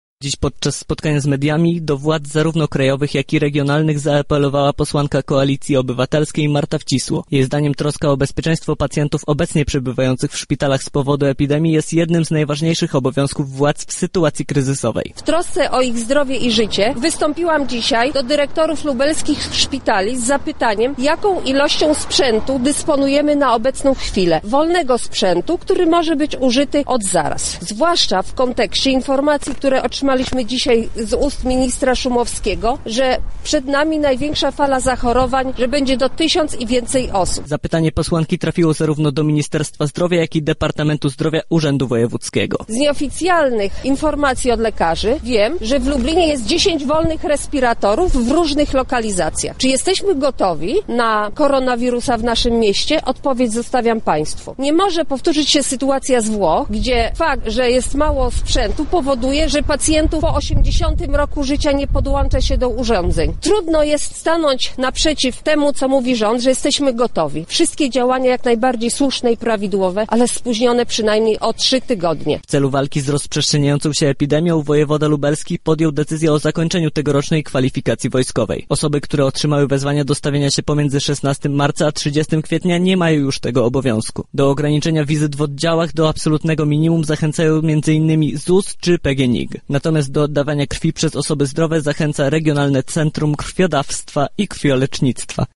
raport-13-03.mp3